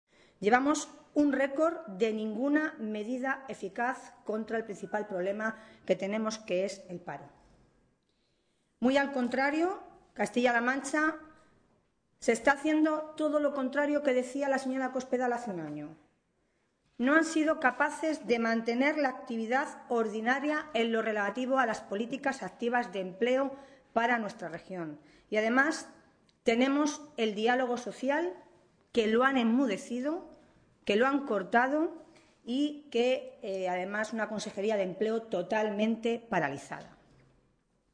Milagros Tolón, portavoz de Empleo del Grupo Parlamentario Socialista
Cortes de audio de la rueda de prensa